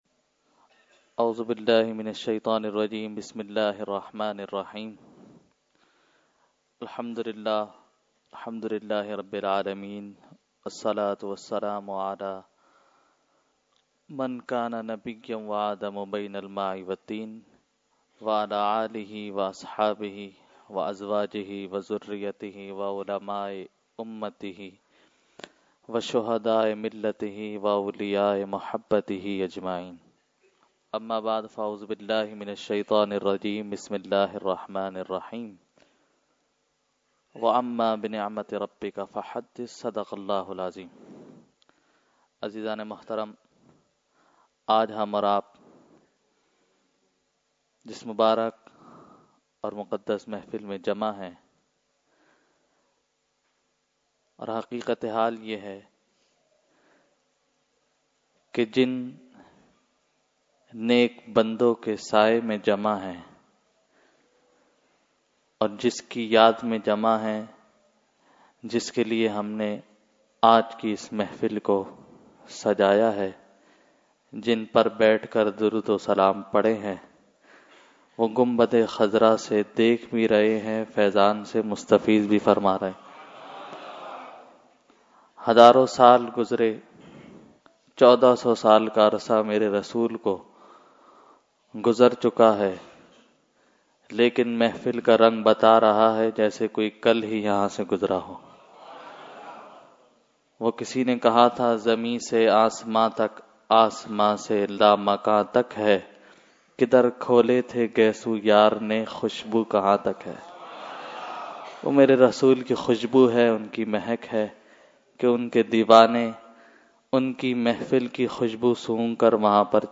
Intro Speech – Jashne Subah Baharan 2017 – Dargah Alia Ashrafia Karachi Pakistan
Weekly Tarbiyati Nashist held on 12/1/2014 at Dargah Alia Ashrafia Ashrafabad Firdous Colony Karachi.
Category : Speech | Language : UrduEvent : Jashne Subah Baharan 2017